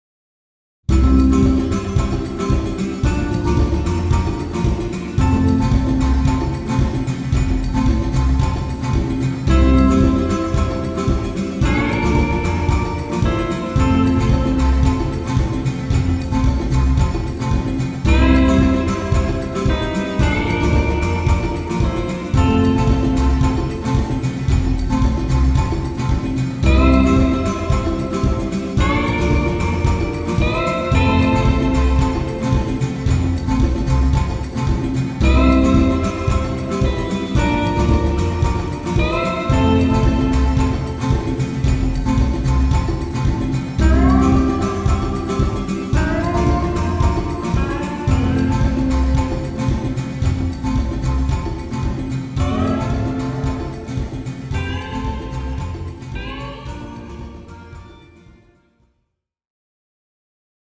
Medium Plate/Room Effect Samples
Freeverb3_VST ProG Reverb
Preset - Small Room Bright
MPlate_ProG_Small_Room_Bright.mp3